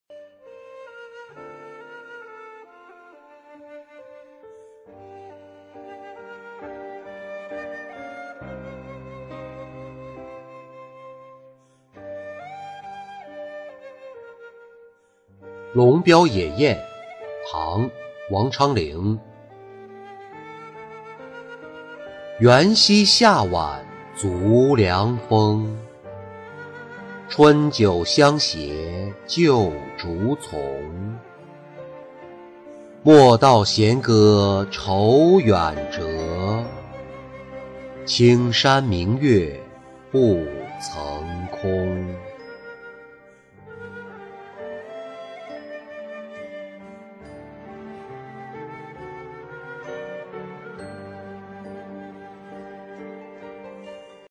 龙标野宴-音频朗读